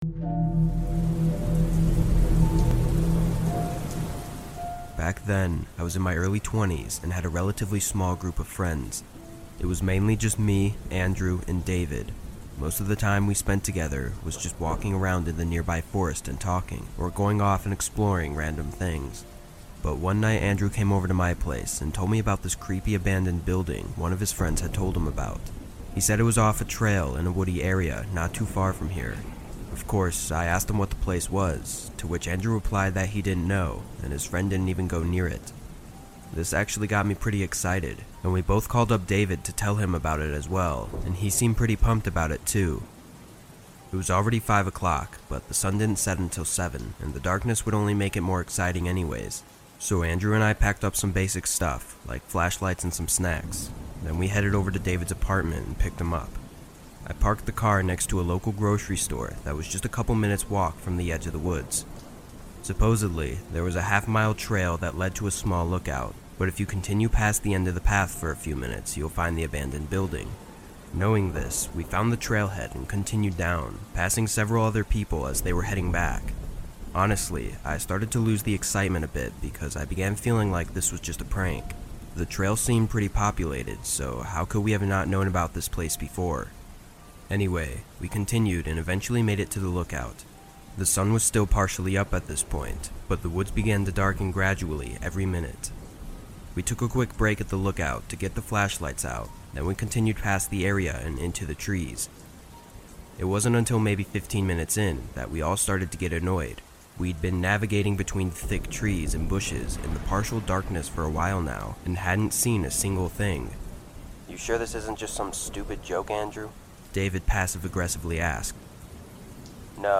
Abandoned Places Horror Stories That Will Keep You Up All Night | With Rain Sounds
All advertisements are placed exclusively at the beginning of each episode, ensuring complete immersion in our horror stories without interruptions.